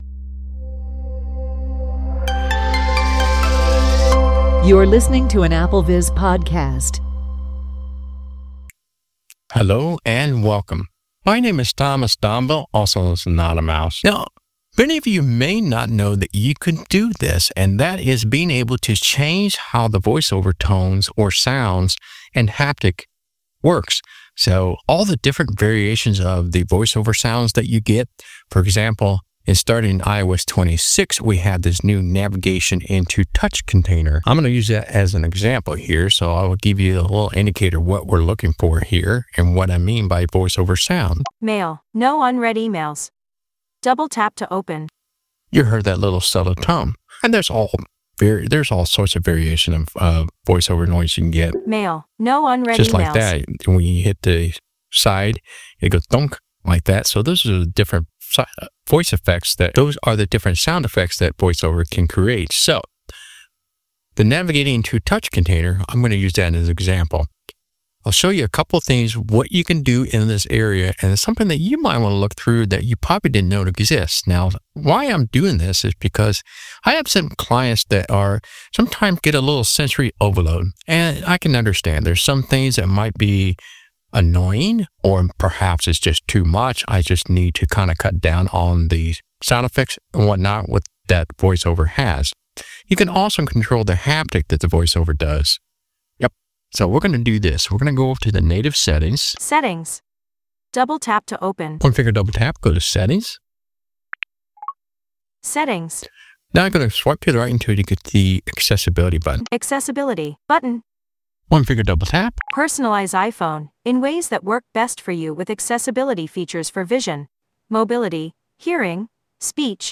• Preview the sound.